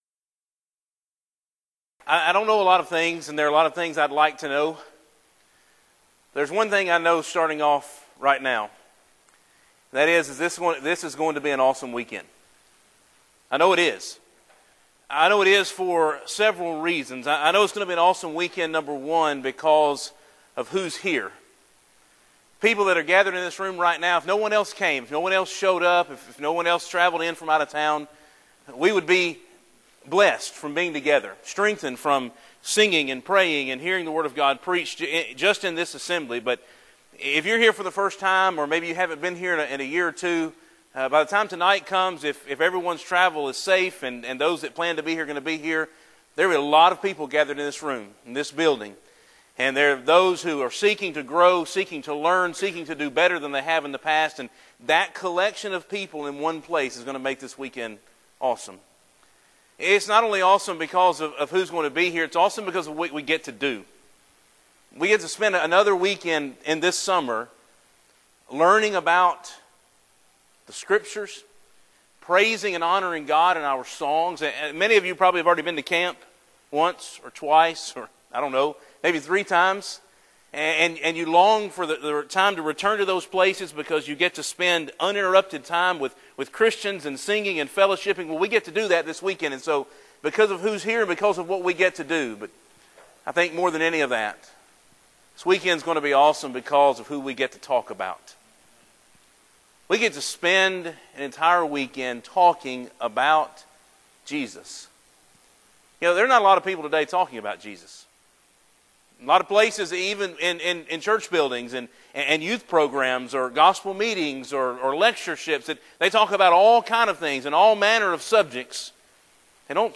Event: Discipleship U 2016
Youth Sessions